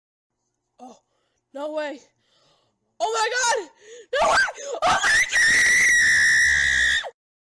Reactions
Oh My God!! Girl Screaming